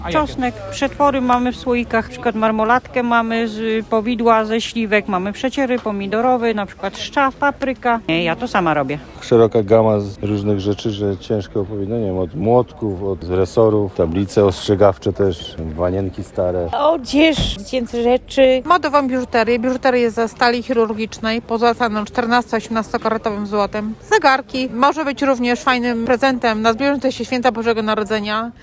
Ponad 500 stanowisk i tysiące zielonogórzan – pomimo porannego mrozu zielonogórzanie chętnie kupują na bazarze przy al. Zjednoczenia.